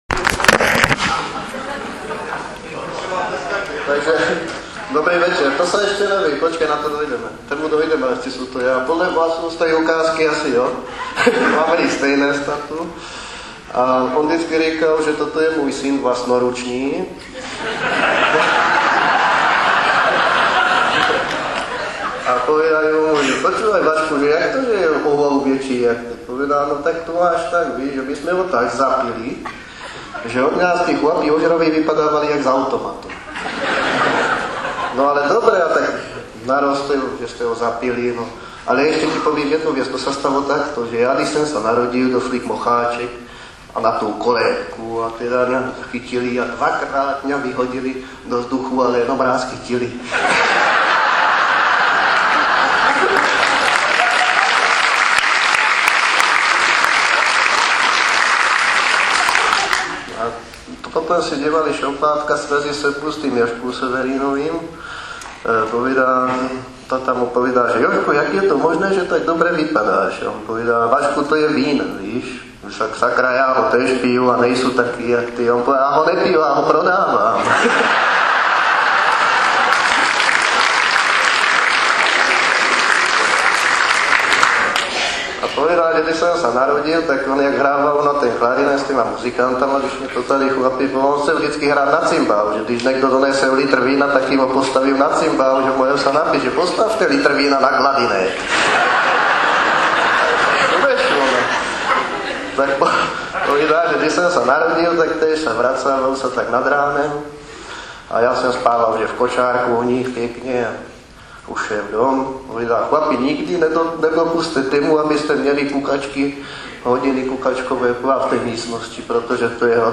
Kulturák byl přeplněný, někteří lidé museli i stát.
slušnou úroveň měli i lidoví vypravěči, kteří vystupovali.